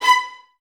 Index of /90_sSampleCDs/Miroslav Vitous - String Ensembles/23 Violins/23 VS Stacc